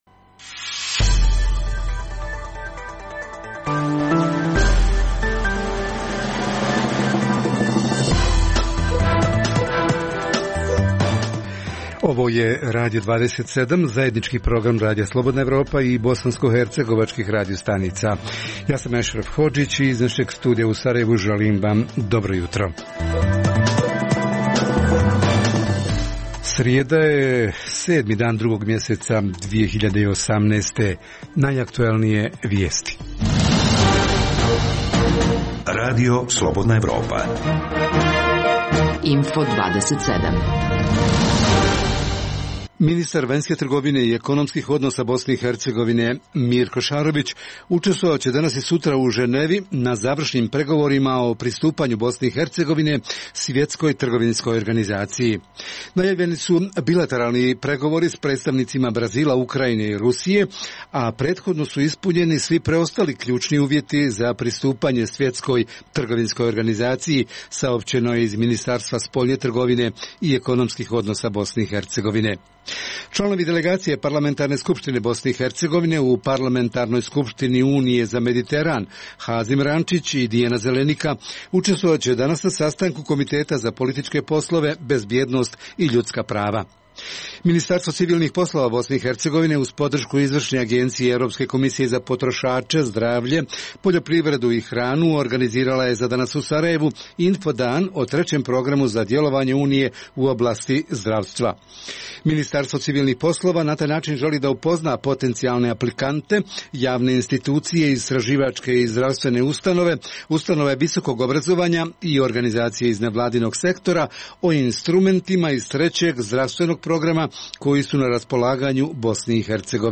O tome konkretnije priče donose naši reporteri i reporterke iz Višegrada, Tuzle, Zvornika i Olova, a u srijedom re